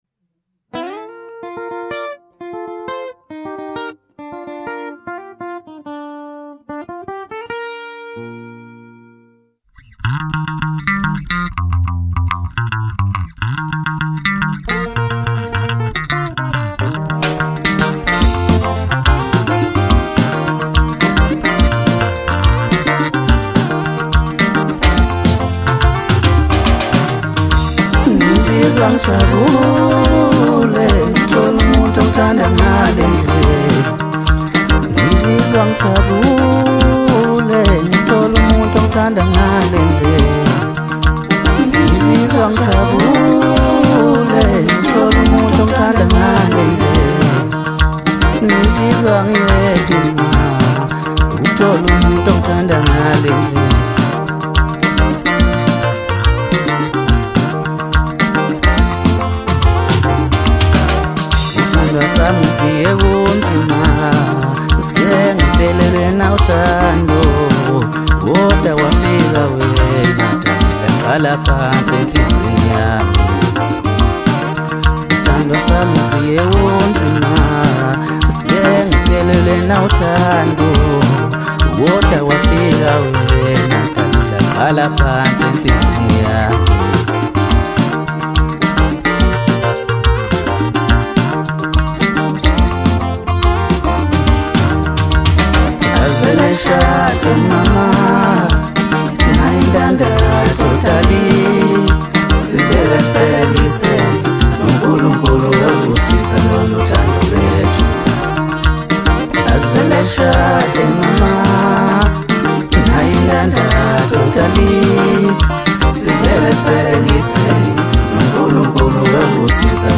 Home » Maskandi » DJ Mix » Hip Hop
South African Maskandi Music maker